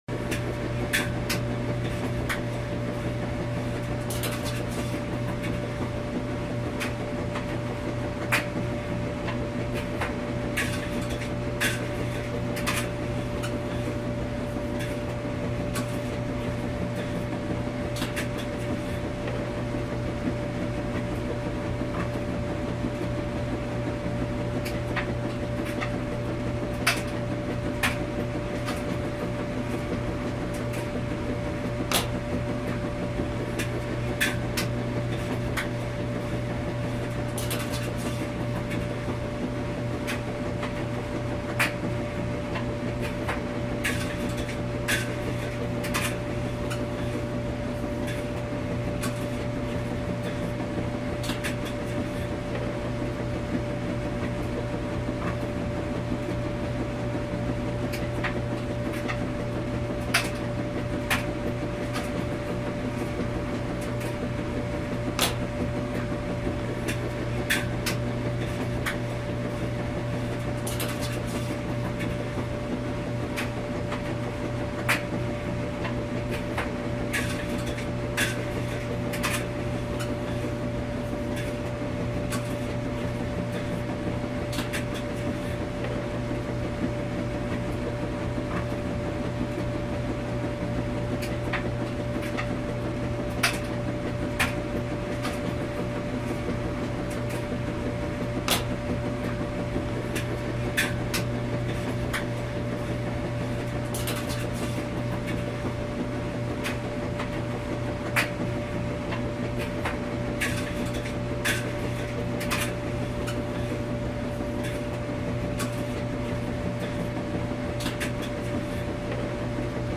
Звуки стиральной доски